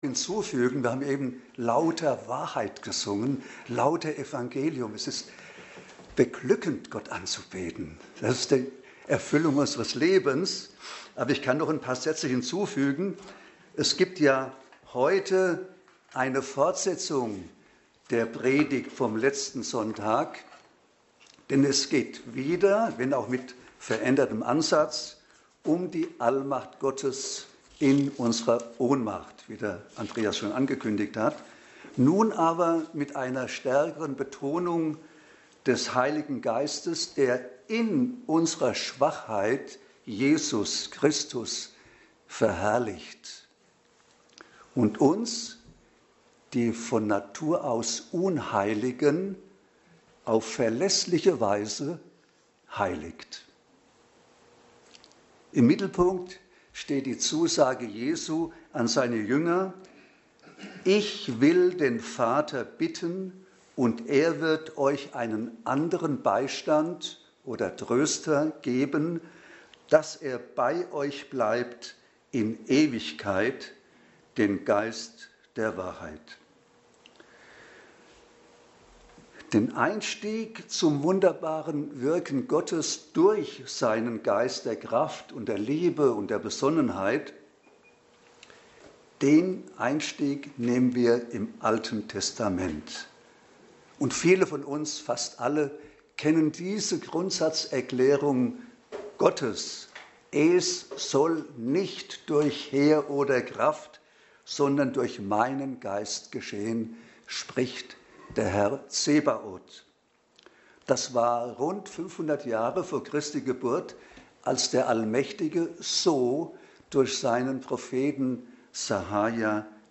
Dienstart: Predigt Themen: Allmacht Gottes , Heiliger Geist , Heiligung